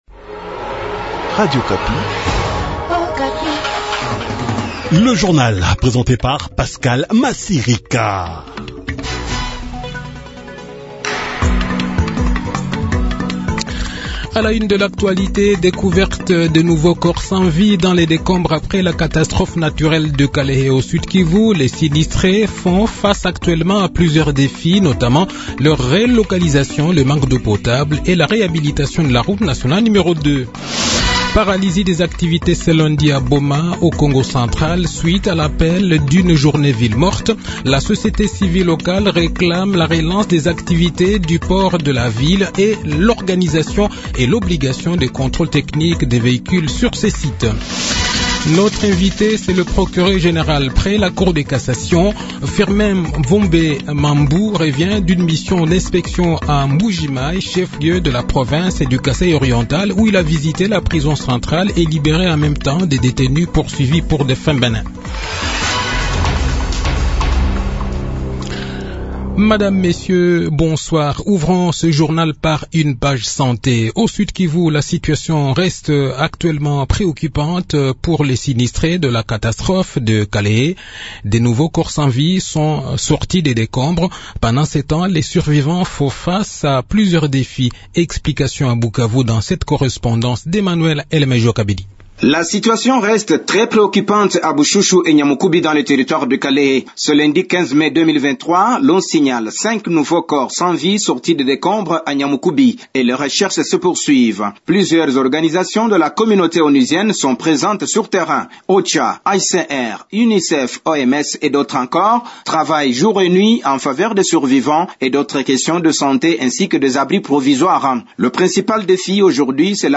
Le journal de 18 h, 15 Mai 2023